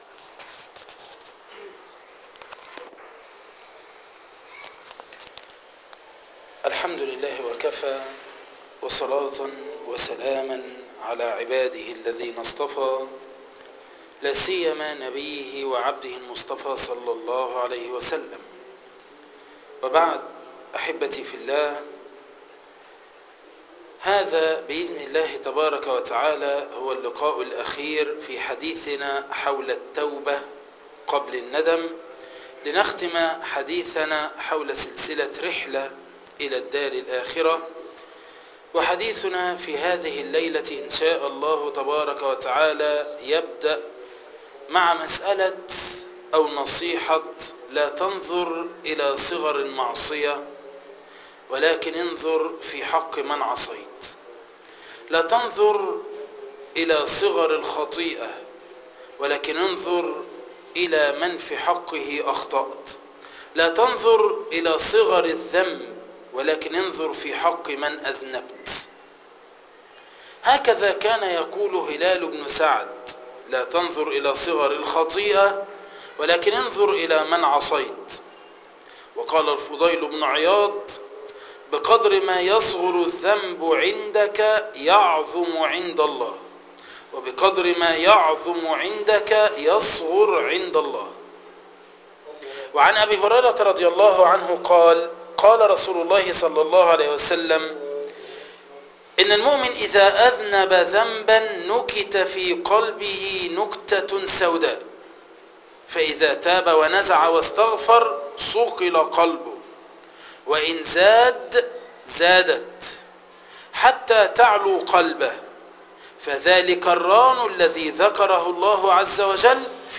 عنوان المادة التوبة قبل الندم الدرس الرابع تاريخ التحميل الأحد 24 فبراير 2013 مـ حجم المادة 11.21 ميجا بايت عدد الزيارات 859 زيارة عدد مرات الحفظ 255 مرة إستماع المادة حفظ المادة اضف تعليقك أرسل لصديق